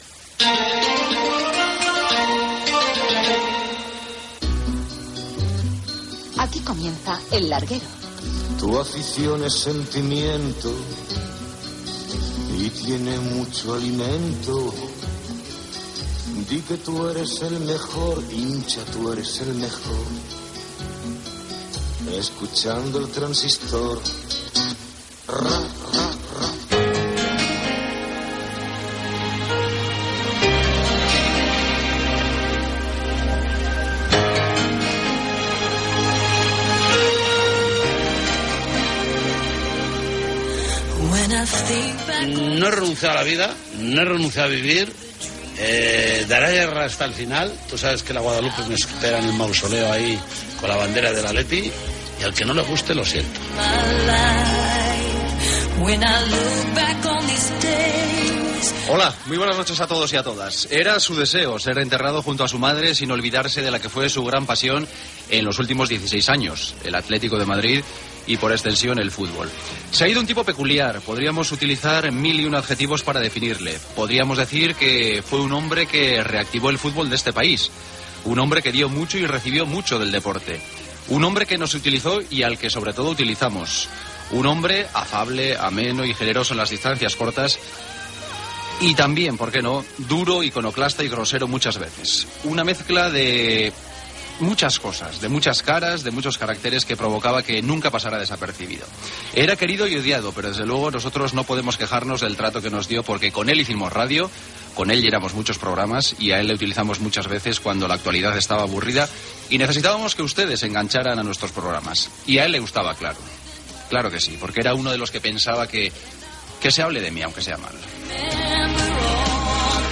69ee4a72af181f54dbdebaa2493d7374e0be83b9.mp3 Títol Cadena SER Emissora Ràdio Barcelona Cadena SER Titularitat Privada estatal Nom programa El larguero Descripció Programa especial dedicat a Jesús Gil, expresident de l'Atlético de Madrid i exalcalde de Marbella, amb motiu de la seva mort. Careta del programa, presentació inicial, perfil biogràfic de Jesús Gil, intervenció telefònica de José Ramón de la Morena, Connexió amb la clínica on ha mort Gil.
Entrevista al president del Real Madrid Florentino Pérez i al del Futbol Club Barcelona Joan Laporta Gènere radiofònic Esportiu